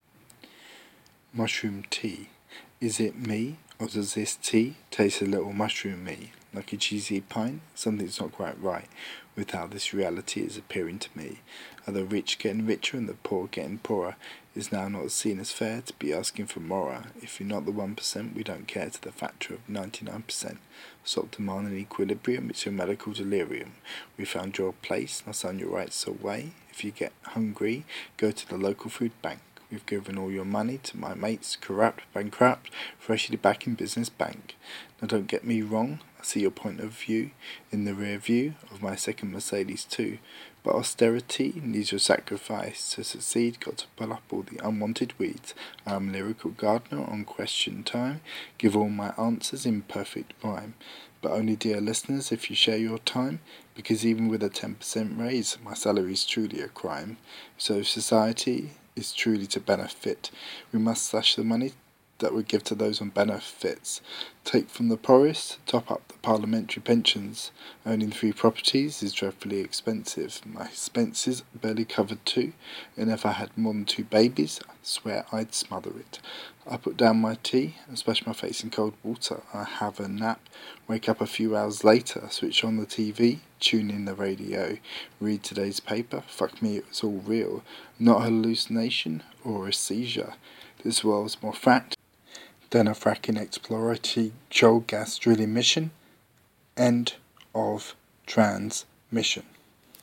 white man rapping (danger cultural appropriation at work)